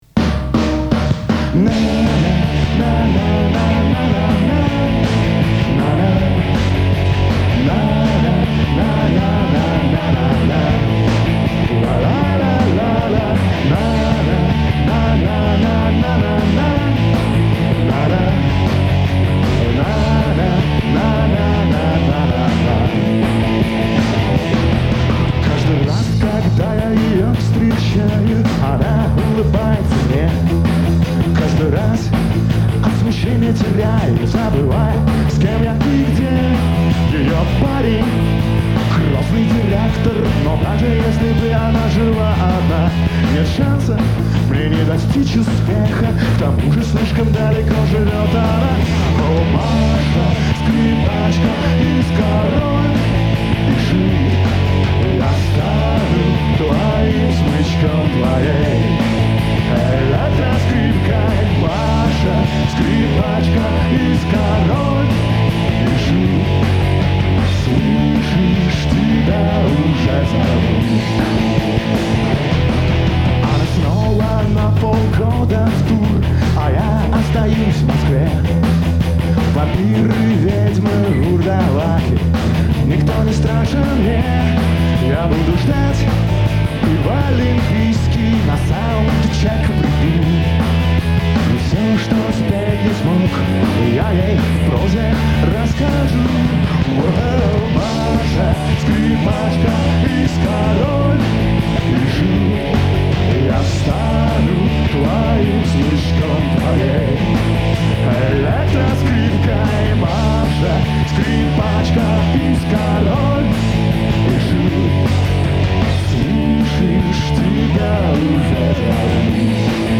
Нашёл и оцифровал кассету